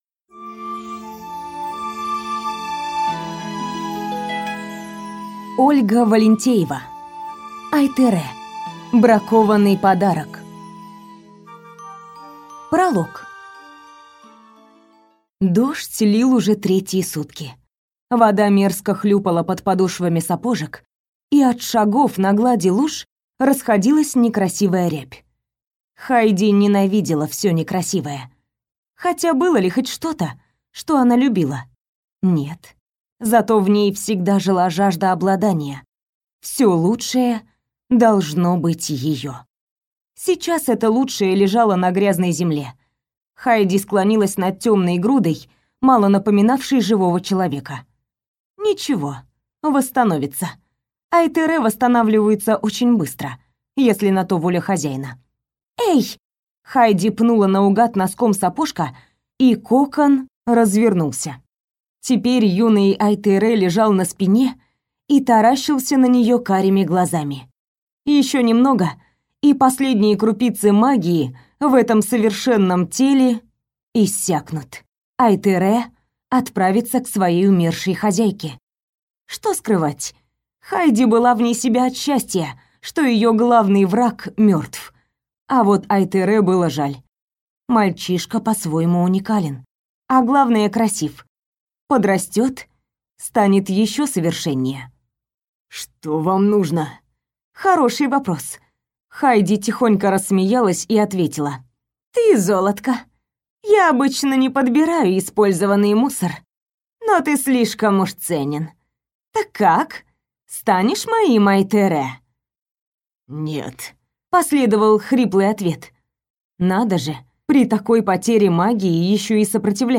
Аудиокнига Ай-тере. Бракованный подарок | Библиотека аудиокниг